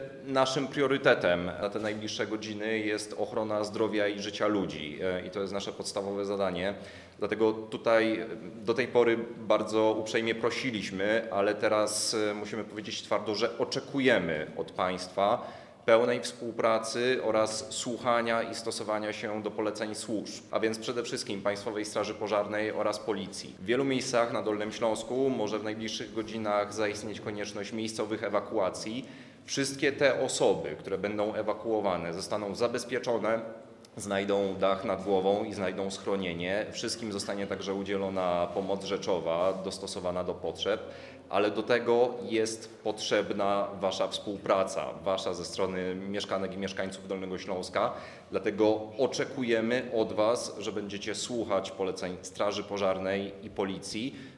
Wicewojewoda zaznacza, że priorytetem na najbliższe godziny jest ochrona zdrowia i życia ludzi. W związku z tym, Piotr Kozdrowicki ponawia apel o stosowanie się do poleceń wydawanych przez służby ratunkowe.